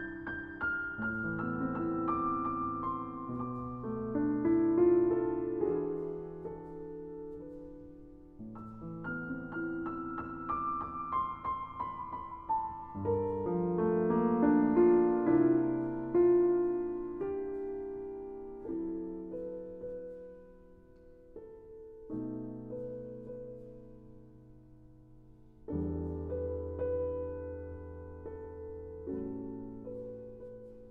musique classique : interprète : piano